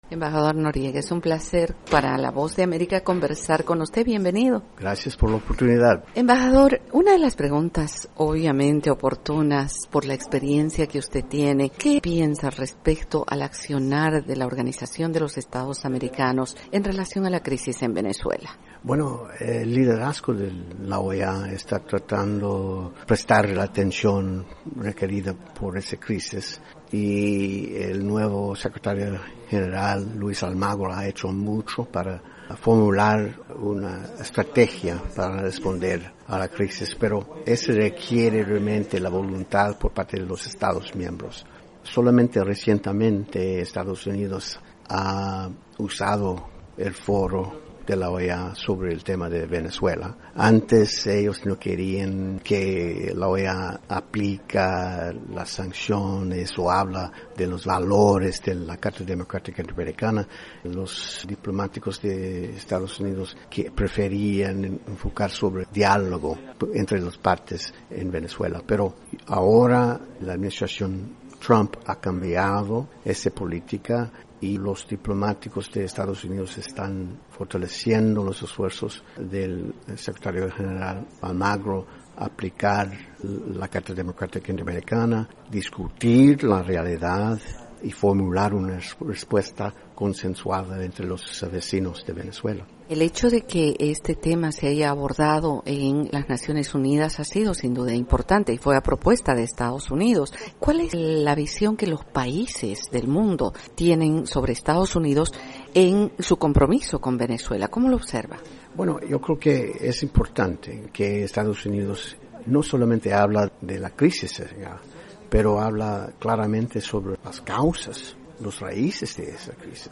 Roger Noriega, ex subsecretario adjunto del Departamento de Estado para el Hemisferio Occidental y ex embajador de Estados Unidos ante la OEA, dijo en entrevista con la Voz de América, que hay una sensación diferente por la forma en la que hoy se encara el problema.